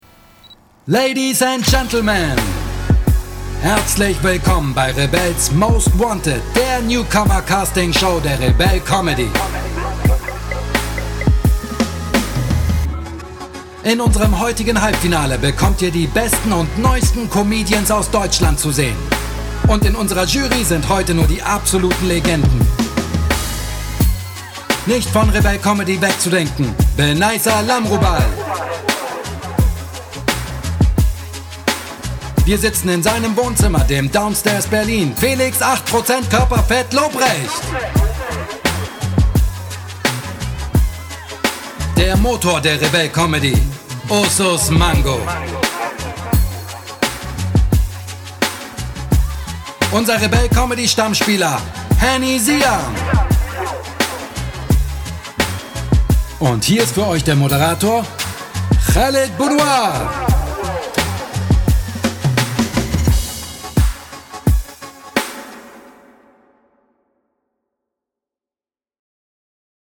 dunkel, sonor, souverän
Mittel minus (25-45)
Presentation